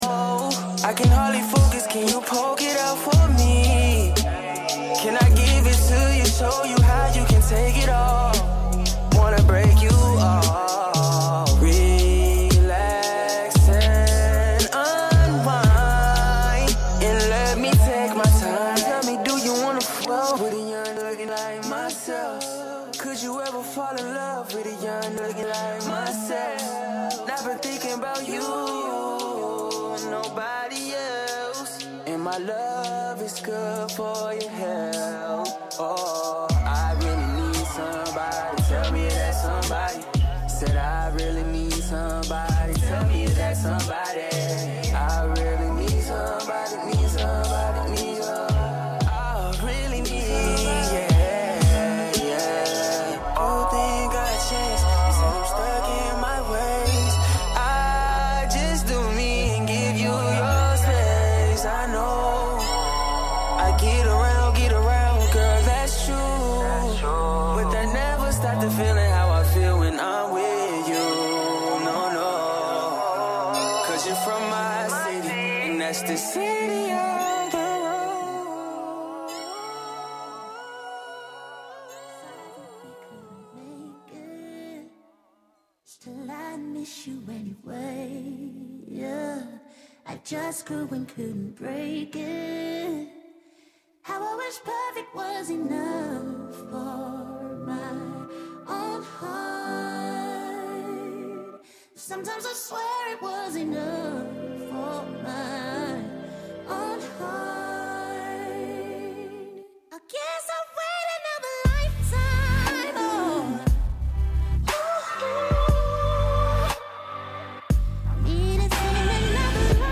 On this show, you’ll hear the recent news, personal experiences and a diverse selection of music.